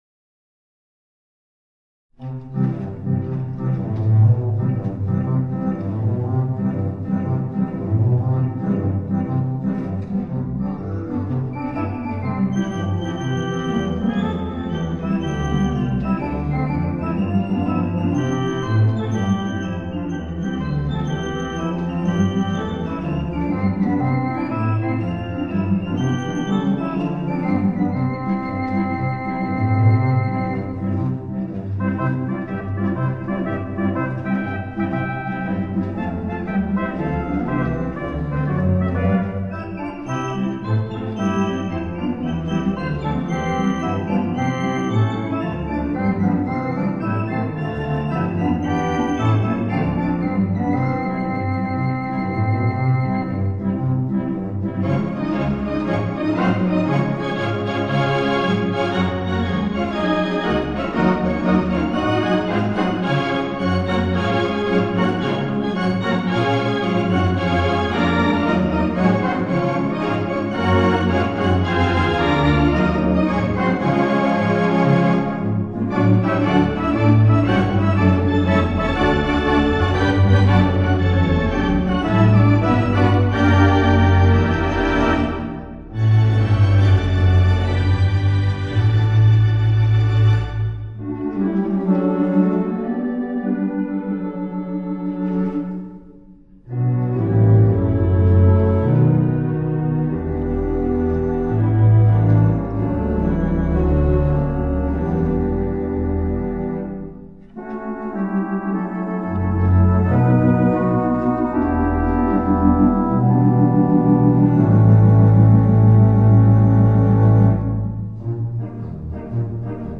The Mighty WurliTzer on the stage